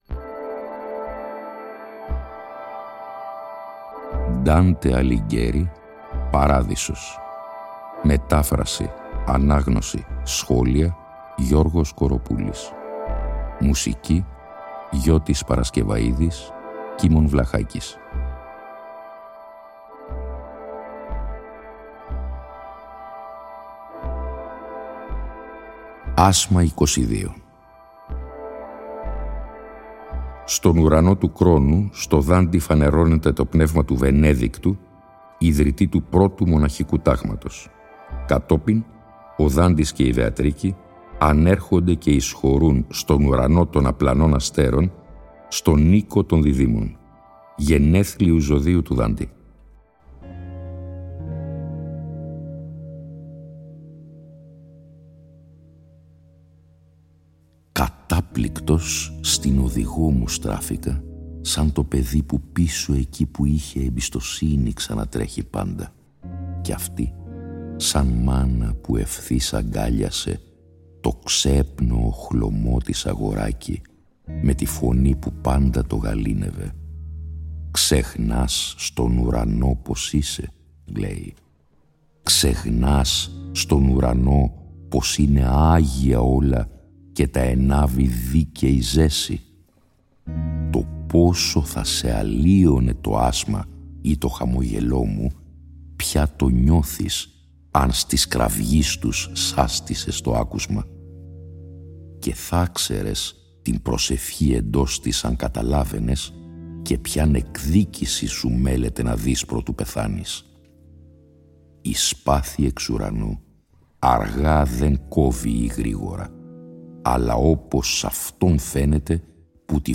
Η ανάγνωση των 33 ασμάτων του «Παραδείσου», σε 21 ημίωρα επεισόδια, (συνέχεια της ανάγνωσης του «Καθαρτηρίου», που είχε προηγηθεί) συνυφαίνεται και πάλι με μουσική